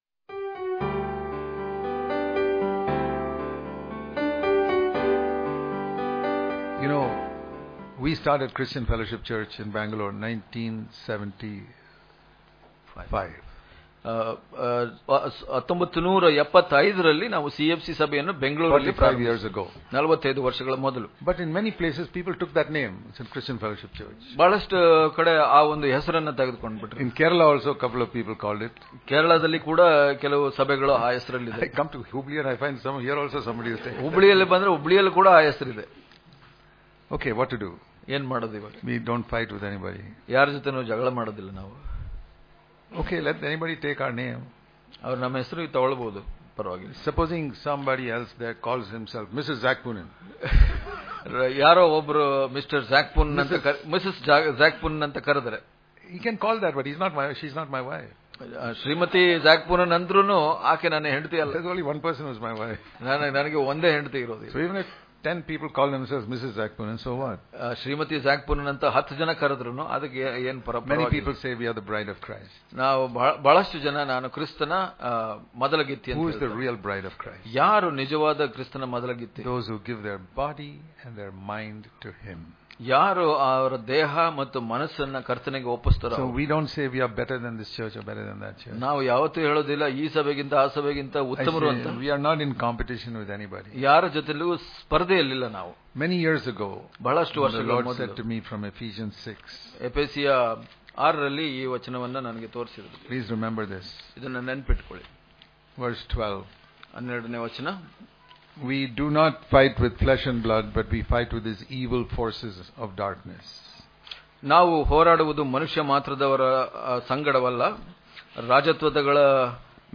May 9 | Kannada Daily Devotion | The Love That The Holy Spirit Puts In Our Heart Daily Devotions